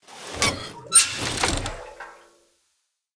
jetpackTank.ogg